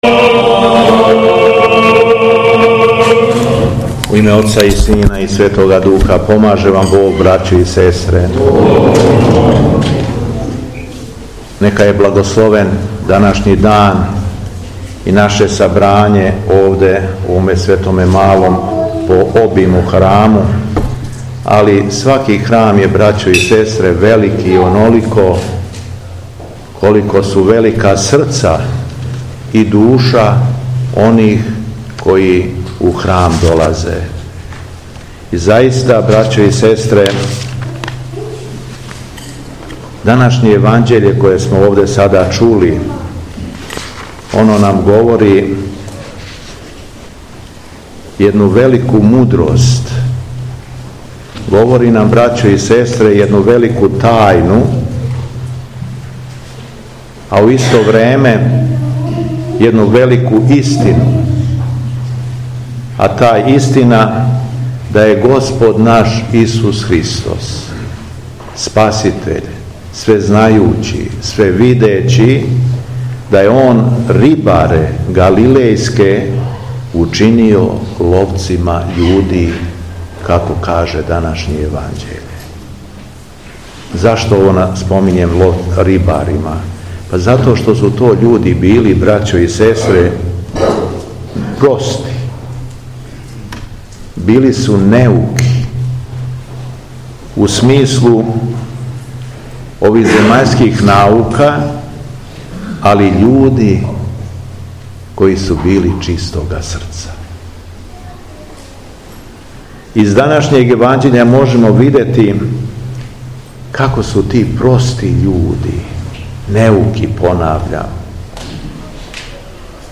Његово Високопреосвештенство Митрополит шумадијски Господин Јован началствовао је 22. јуна 2025. године, у другу недељу по Духовима, Светом архијерејском литургијом у селу Араповац надомак Лазаревца у аријерејском намесништву колубарско-посавском у Храму посвећеном Вазнесењу Господњем.
Беседа Његовог Високопреосвештенства Митрополита шумадијског г. Јована